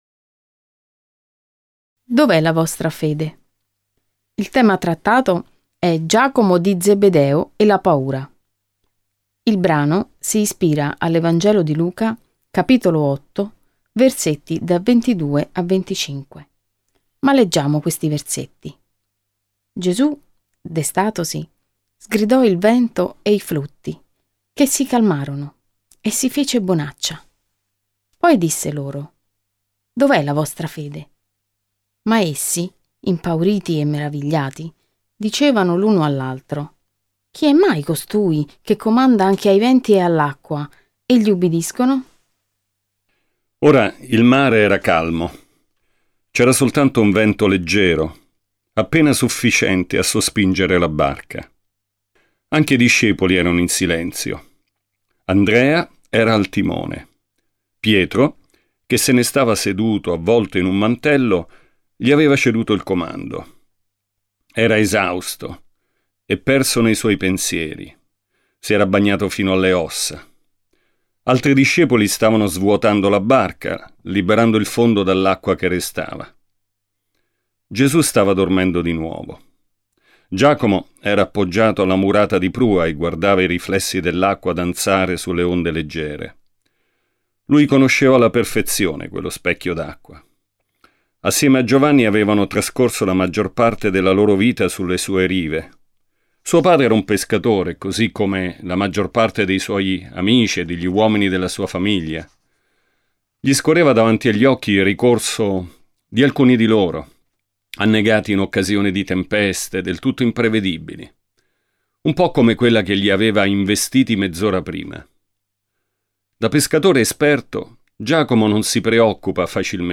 • Lettura Libri
Lettura integrale MP3